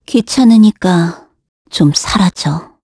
Gremory-Vox_Skill2_kr.wav